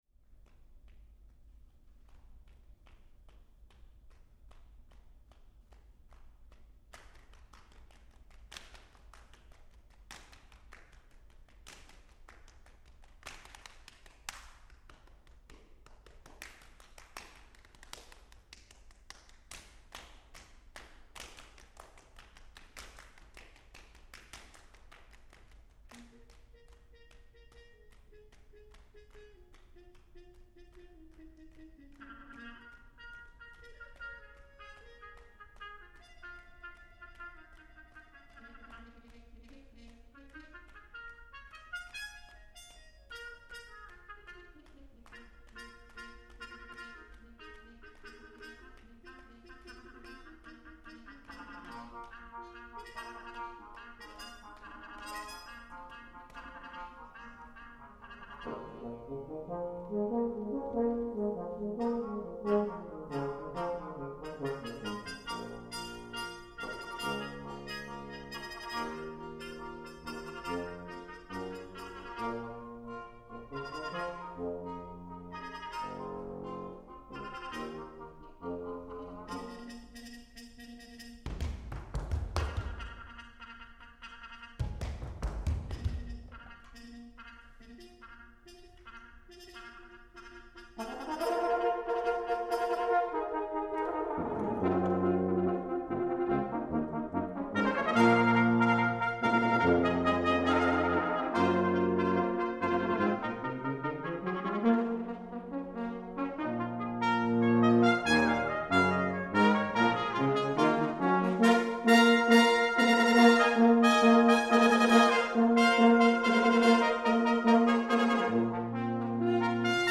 for Brass Quintet (1987)